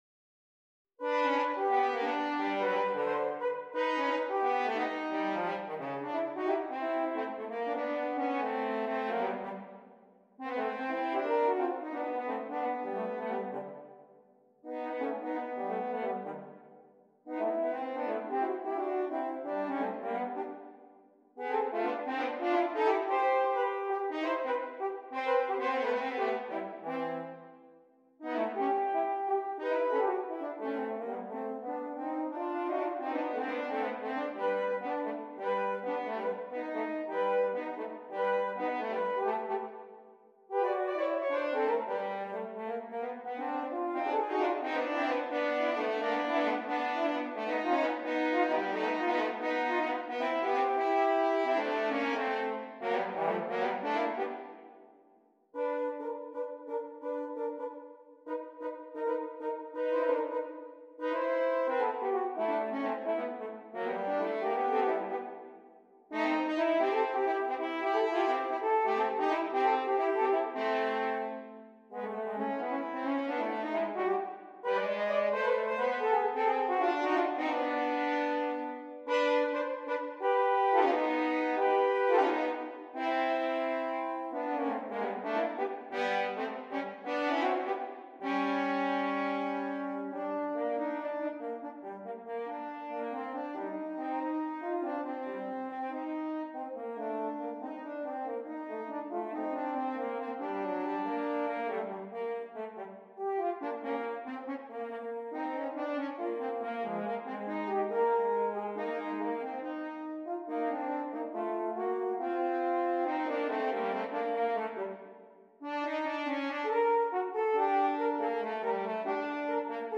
Gattung: Für 2 Hörner F
Besetzung: Instrumentalnoten für Horn
Jazz-Duetten